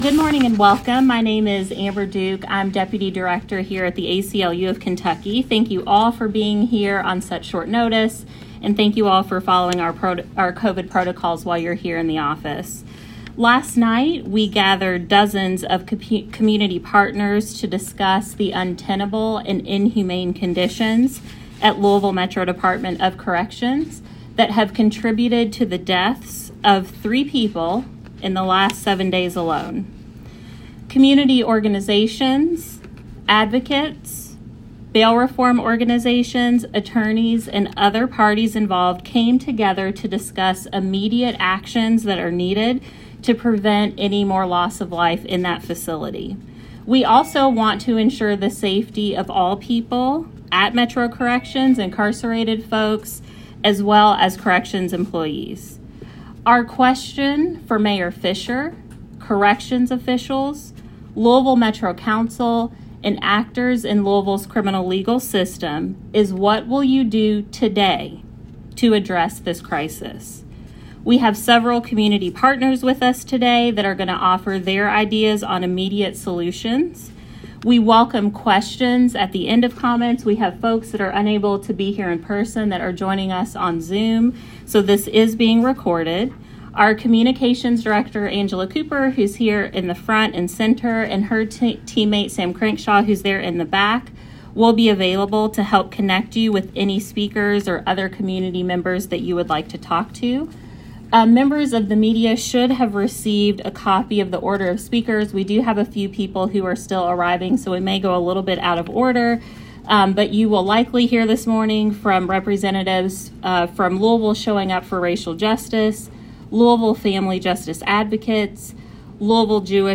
AUDIO: Press Conference Regarding Deaths in Louisville Department of Corrections